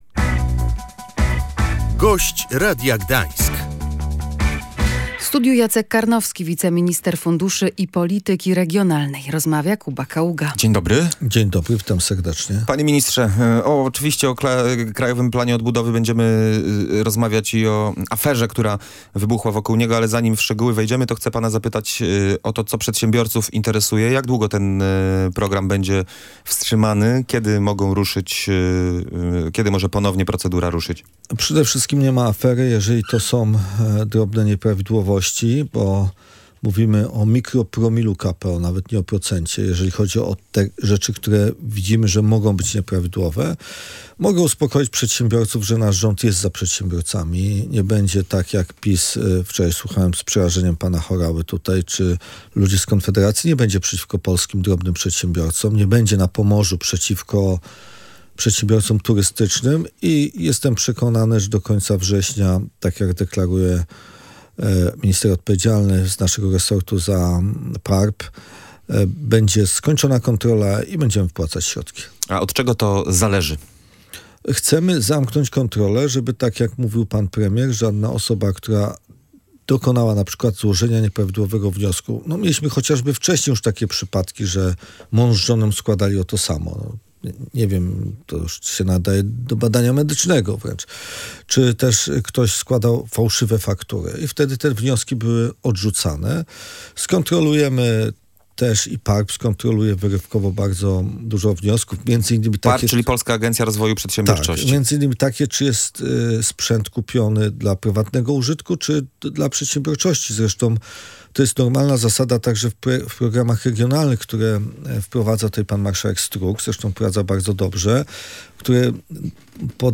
Do końca września potrwa kontrola wniosków przyznanych w ramach Krajowego Planu Odbudowy – mówił w Radiu Gdańsk wiceminister funduszy i polityki regionalnej Jacek Karnowski.